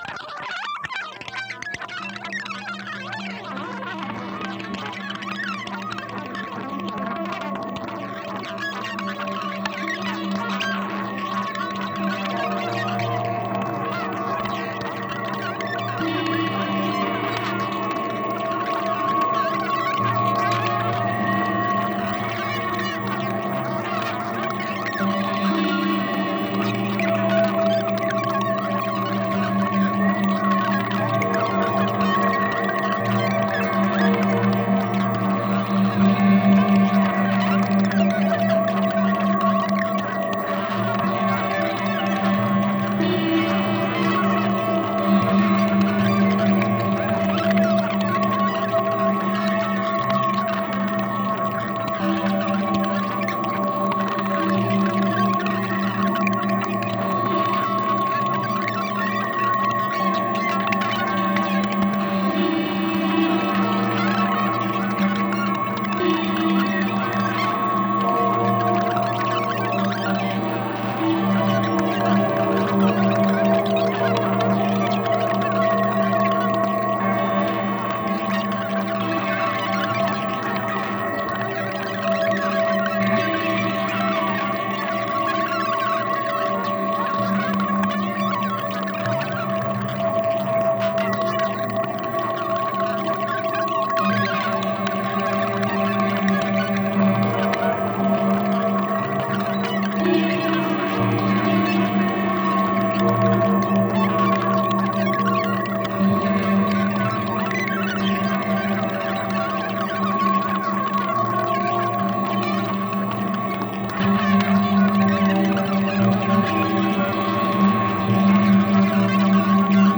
ギター愛好家の方々にはもちろん、現代音楽、先端的テクノ、実験音楽をお好きな方々にもお薦めのアルバムです。